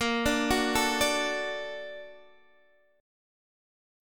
D/Bb chord